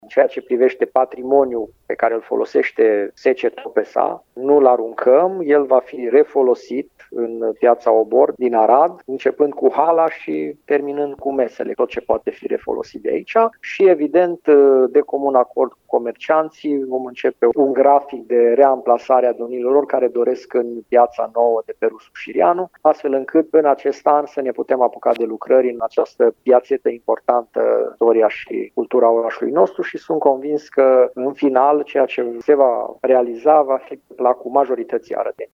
Măsura este luată pentru a permite efectuarea lucrărilor de refacere a zonei, spune primarul Călin Bibarț.